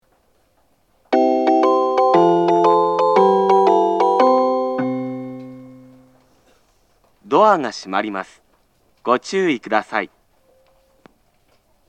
発車メロディー
こちらもスイッチを一度扱えばフルコーラス鳴ります。